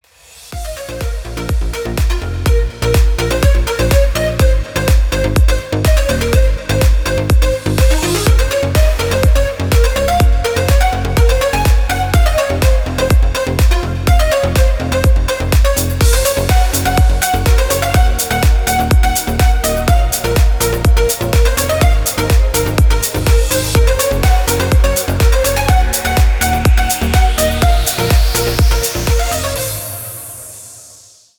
• Качество: 320, Stereo
Electronic
EDM
без слов
энергичные
Стиль: deep house.